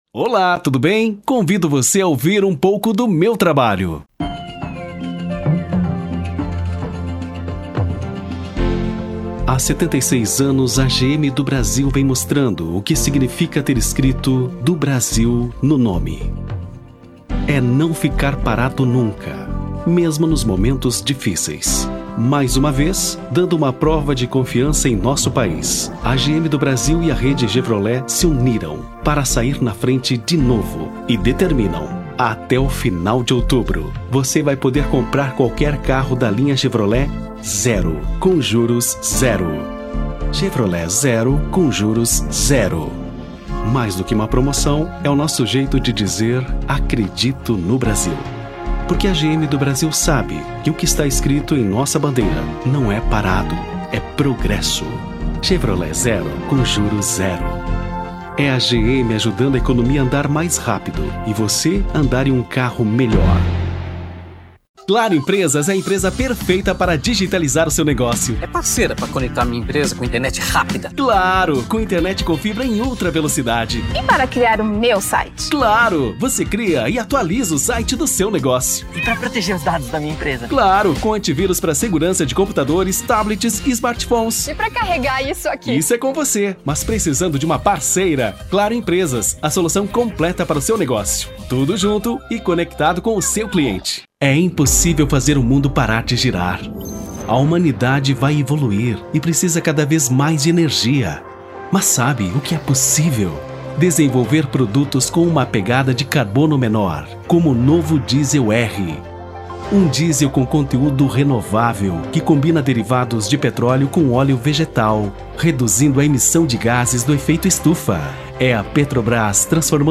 Rio Grande do Sul